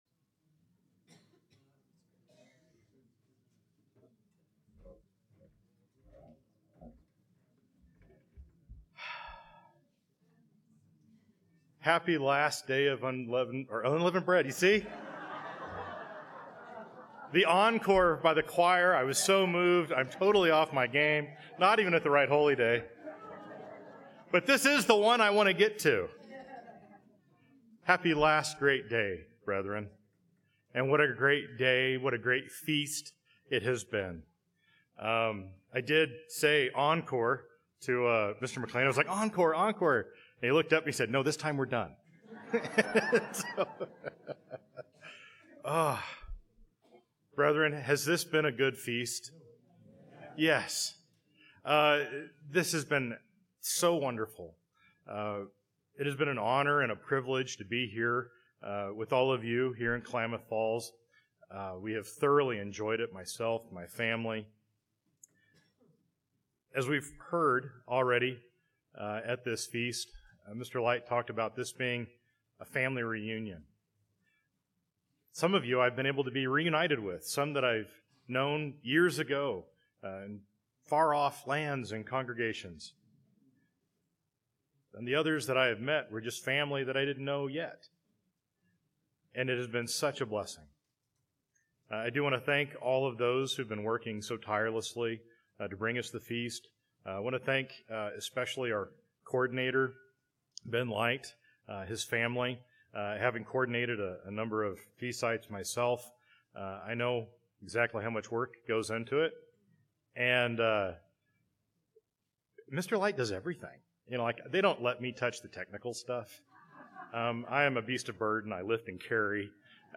Sermon - 8th Day PM - Feast of Tabernacles - Klamath Falls, Oregon
This sermon was given at the Klamath Falls, Oregon 2024 Feast site.